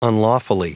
Prononciation du mot unlawfully en anglais (fichier audio)
Prononciation du mot : unlawfully